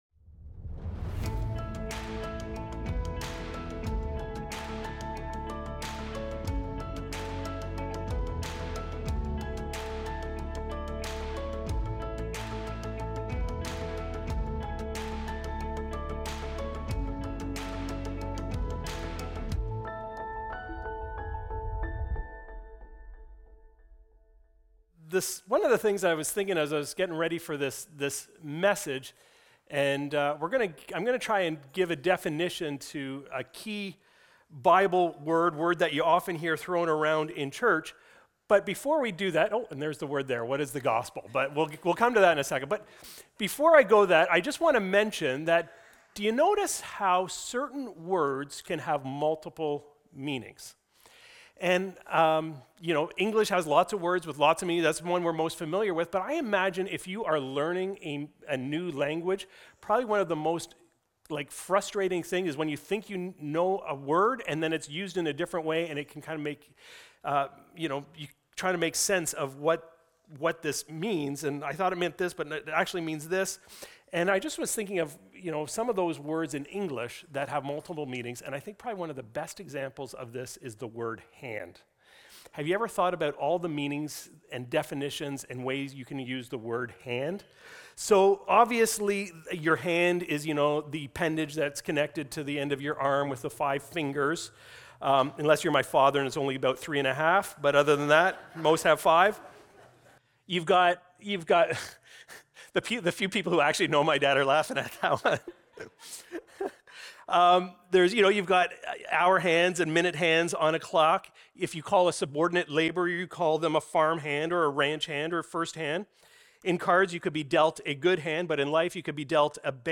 Recorded Sunday, September 7, 2025, at Trentside Bobcaygeon.